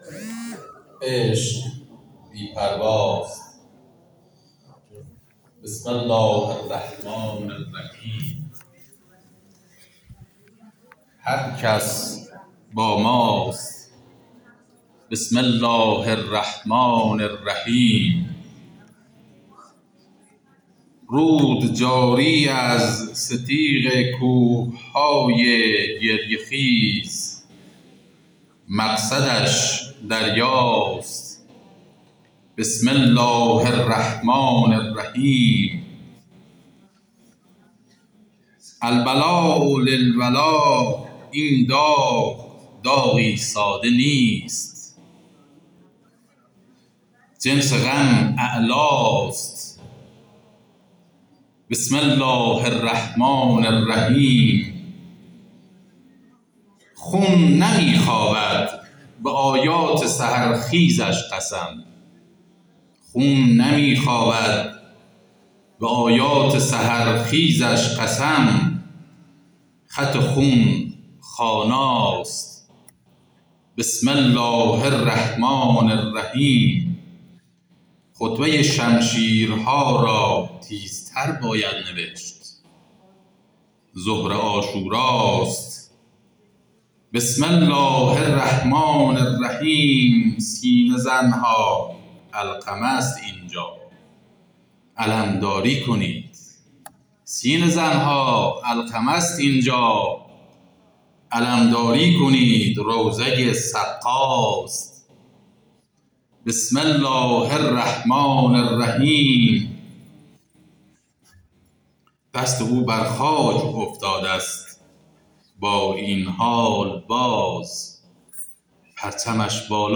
مراسم ششمین سالگرد شهادت سردار سپهبد شهید حاج قاسم سلیمانی با حضور پرشور مردم ولایتمدار، خانواده‌های معظم شهدا، بسیجیان و جمعی از مسئولان، در گلزار شهدای شهرستان باشت برگزار شد.